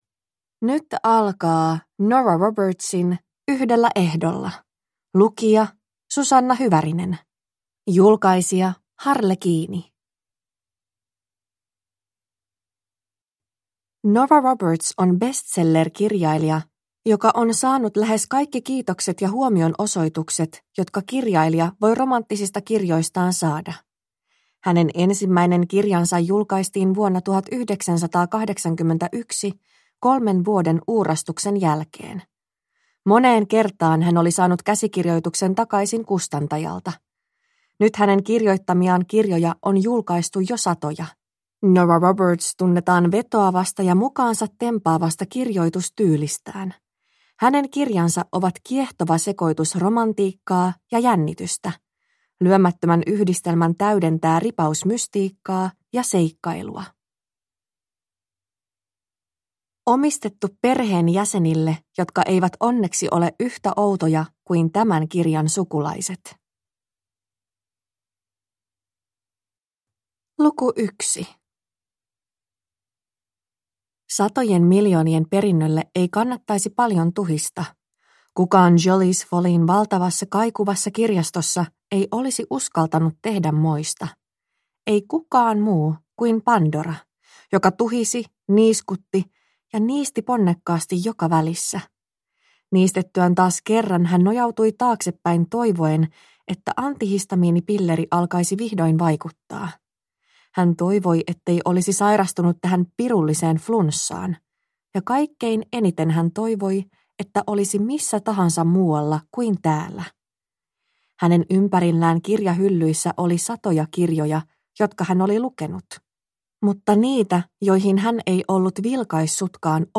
Yhdellä ehdolla – Ljudbok